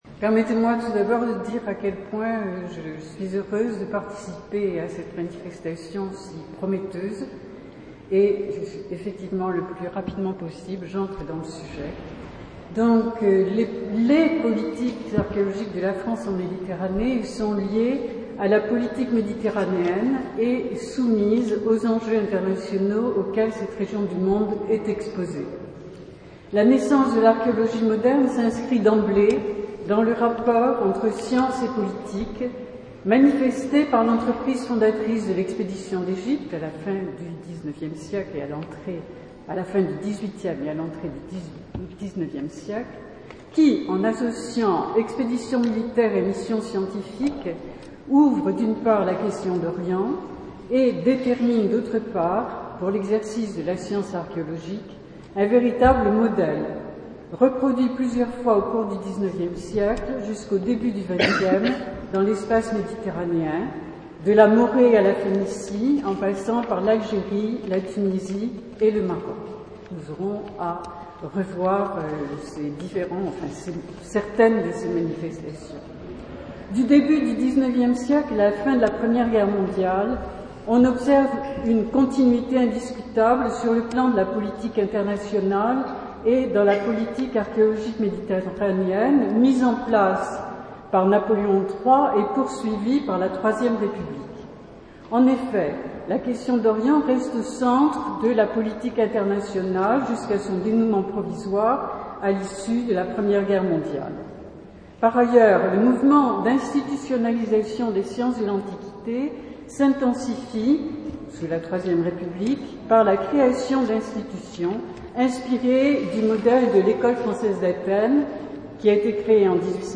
Enregistrements du colloque 2013 (1), Palais Farnèse
Palais Farnese, Ambassade de France